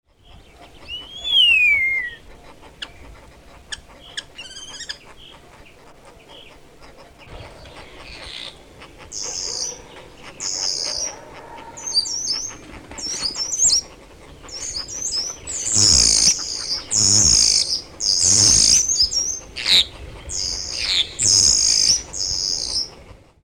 Genre: Animal Sound Recording.